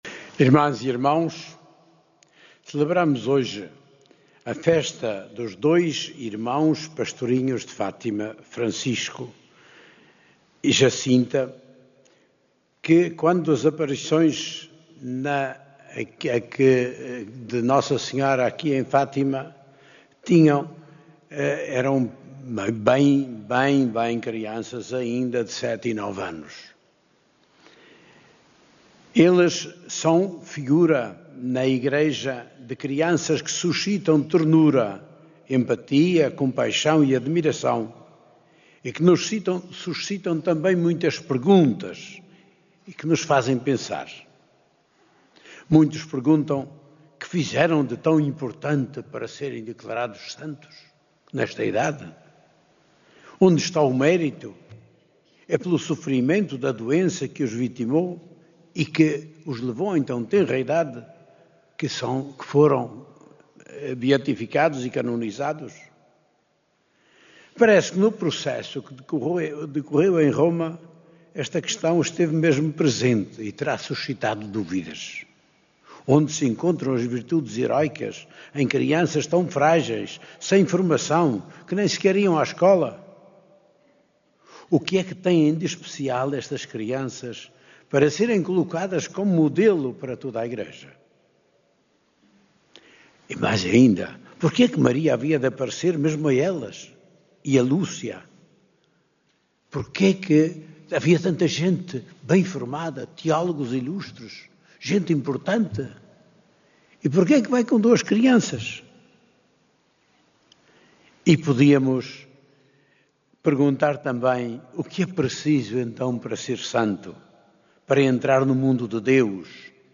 Na manhã de hoje na Basílica da Santíssima Trindade, D. José Ornelas, bispo de Leiria-Fátima, destacou a santidade dos testemunhos de Francisco e Jacinta Marto para a Igreja e a sua atualidade para o mundo.
Na homilia, o sacerdote evocou ainda a figura bíblica de Samuel, evidenciando a importância da escuta ativa da voz de Deus.